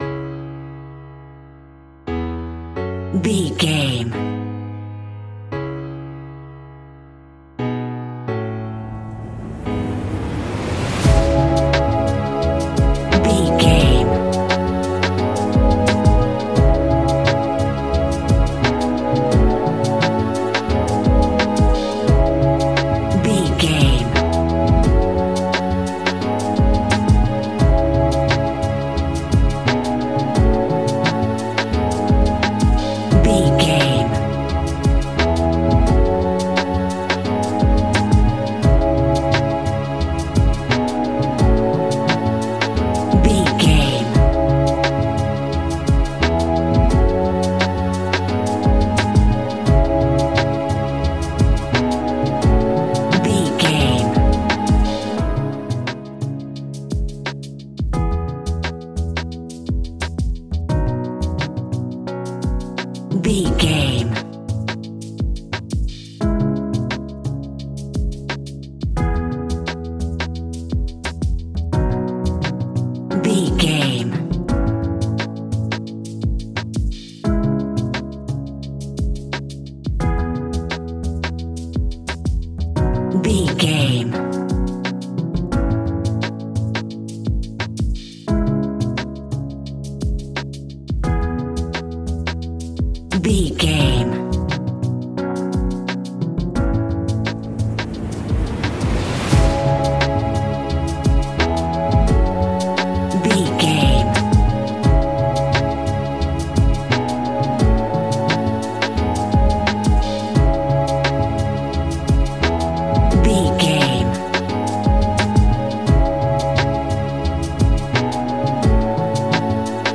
Ionian/Major
chilled
laid back
Lounge
sparse
new age
chilled electronica
ambient
atmospheric
morphing
instrumentals